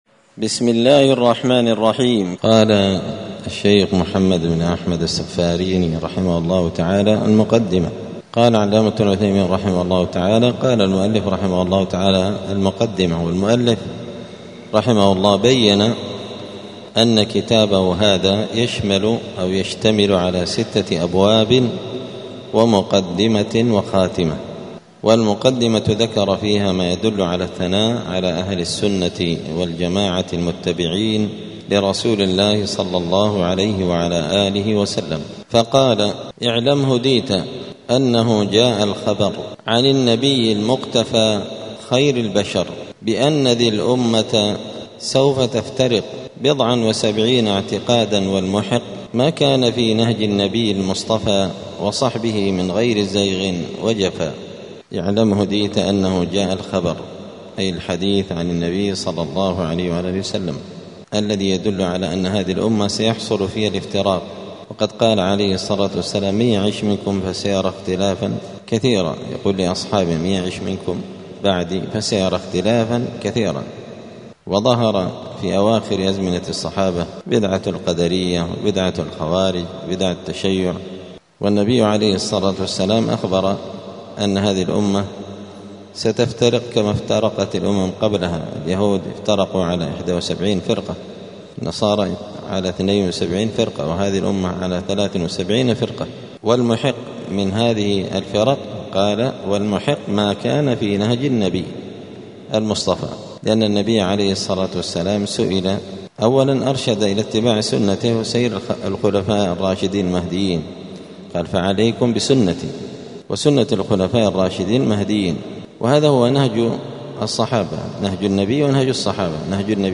دار الحديث السلفية بمسجد الفرقان قشن المهرة اليمن
19الدرس-التاسع-عشر-من-شرح-العقيدة-السفارينية.mp3